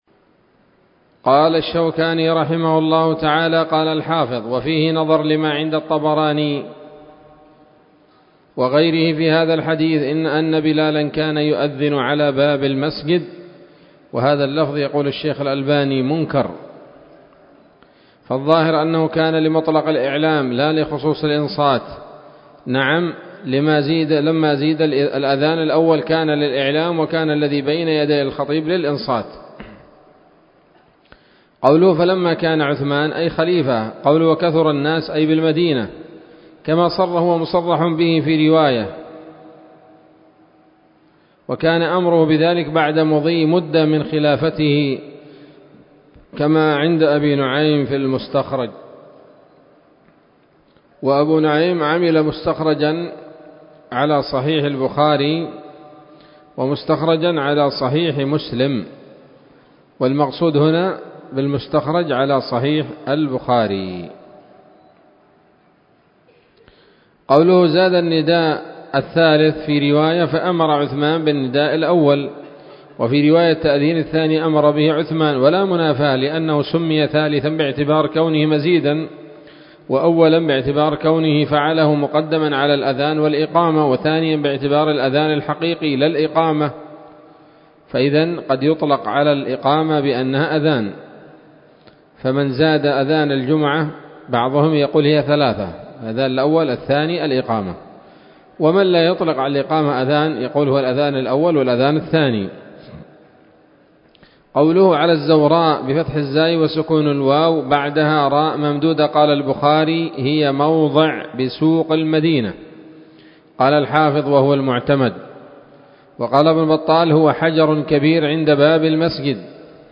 الدرس الخامس والعشرون من ‌‌‌‌أَبْوَاب الجمعة من نيل الأوطار